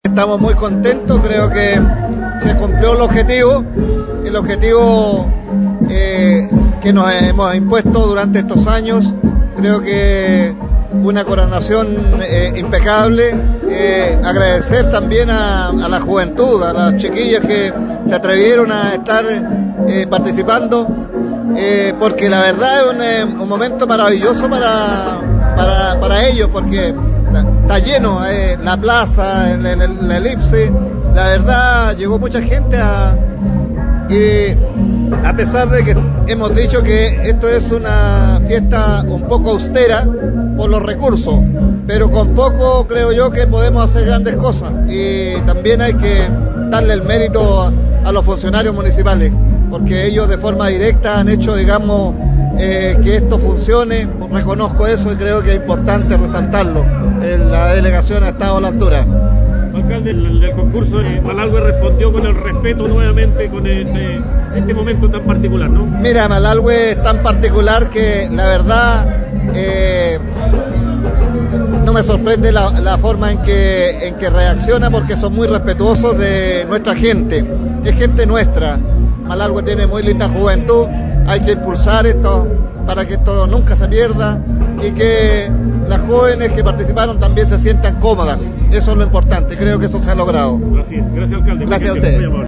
audio-alcalde.mp3